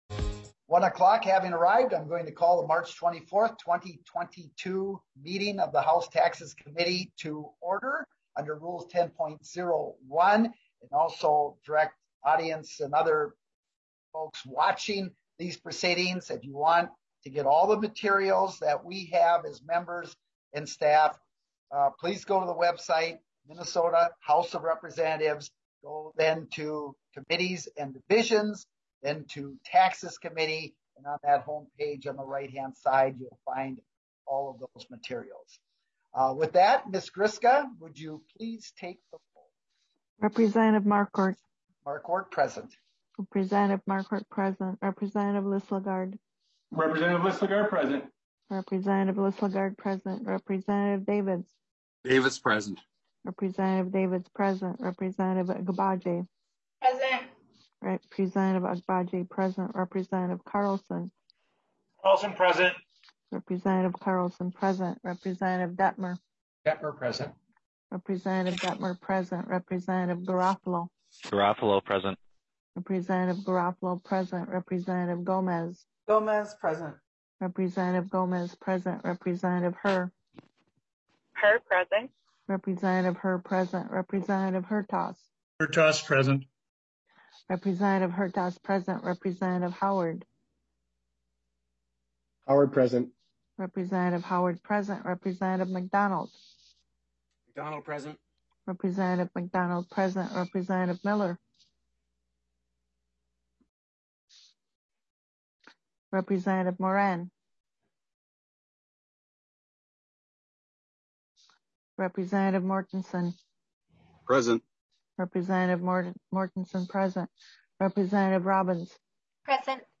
Testimony is limited to two minutes per testifier.